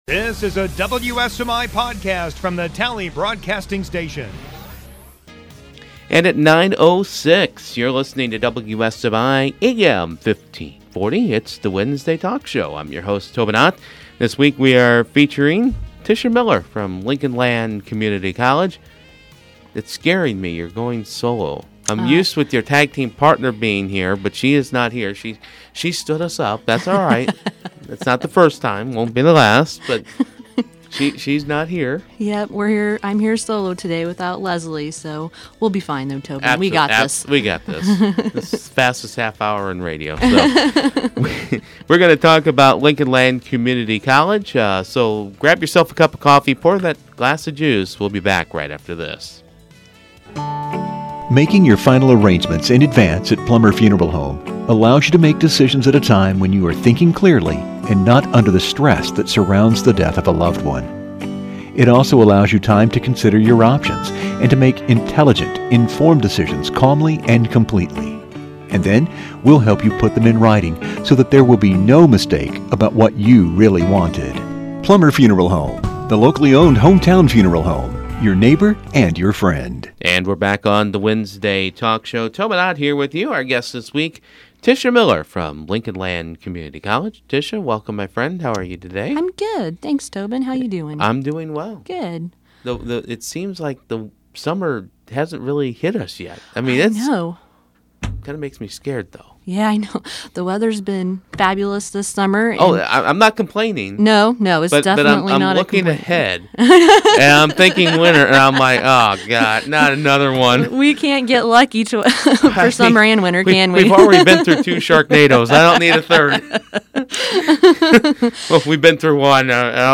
Wednesday Morning Talk Show